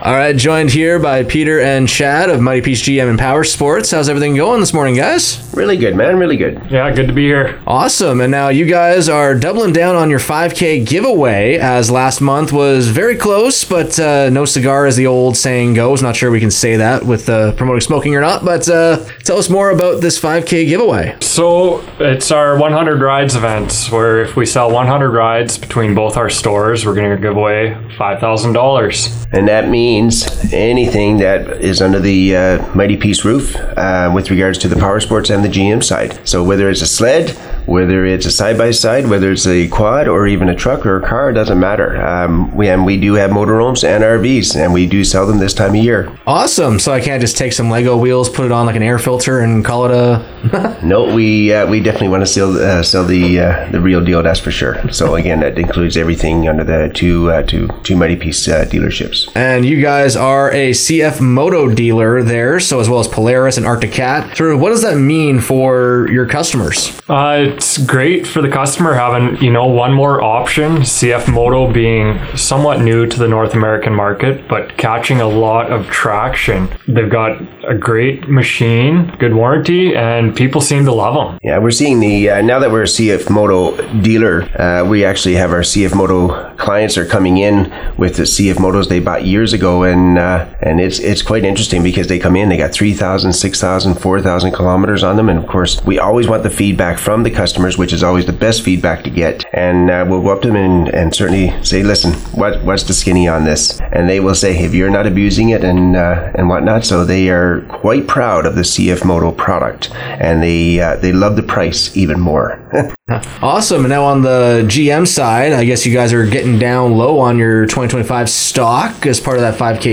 swung by the studio